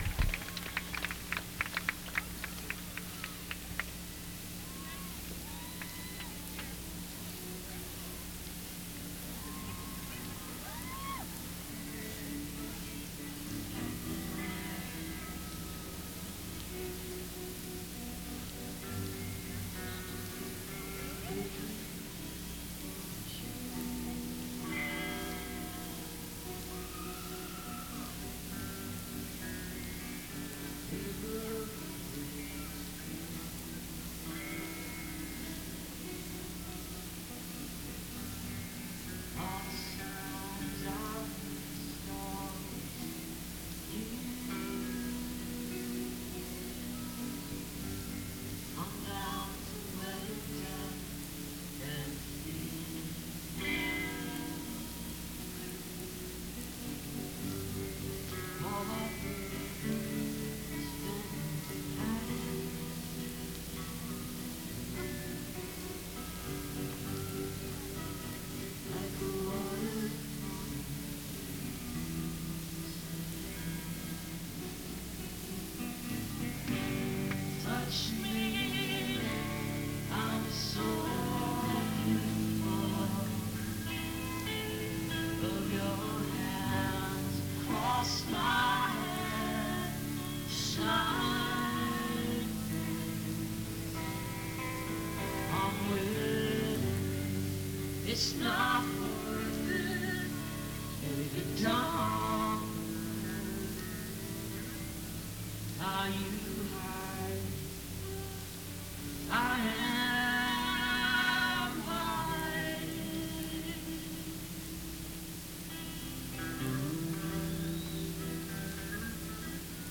newport folk festival - newport, rhode island